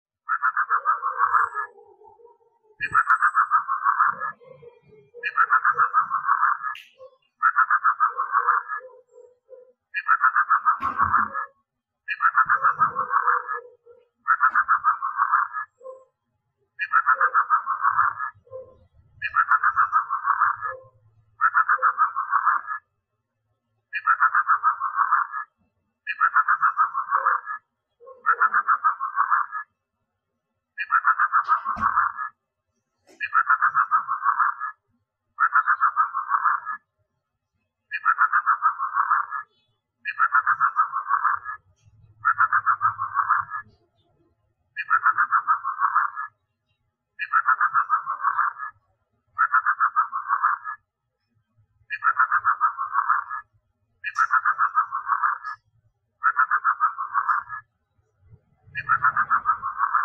Tải Tiếng Mèo rừng kêu MP3